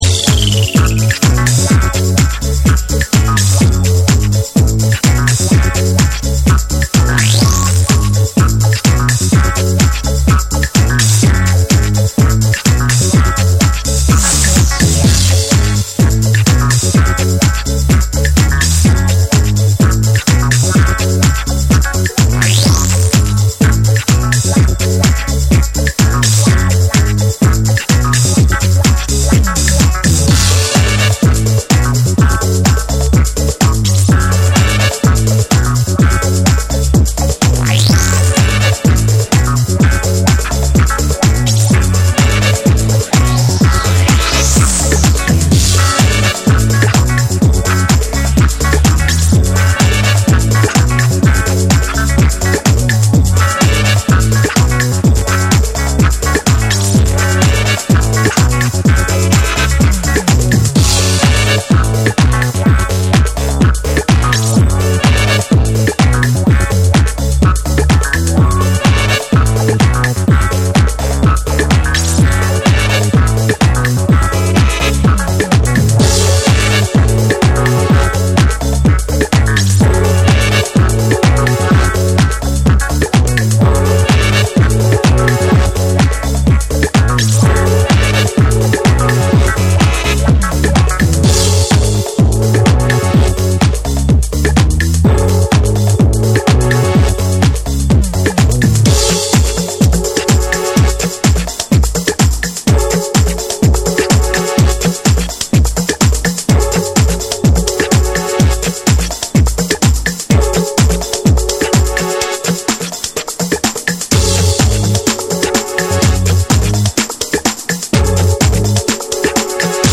パーカッションが絡み、ベースのウネりがファンキーなグルーヴを描く
TECHNO & HOUSE